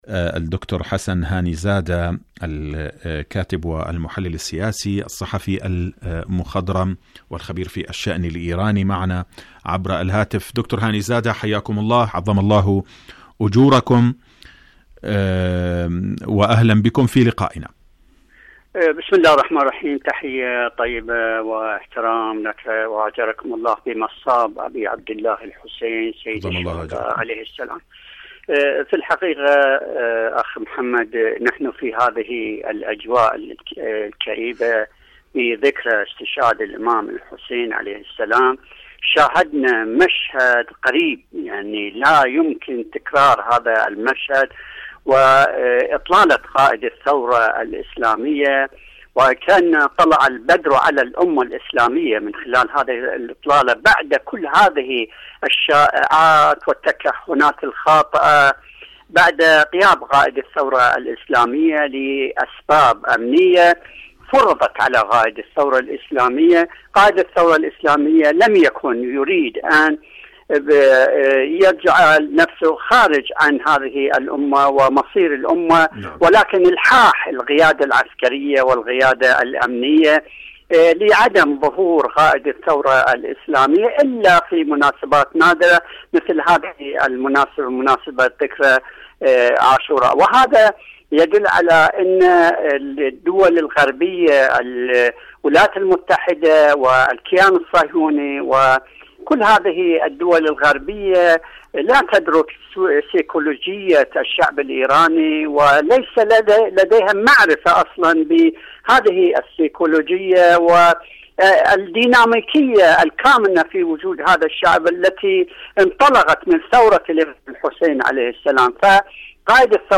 إذاعة طهران- حدث وحوار: مقابلة إذاعية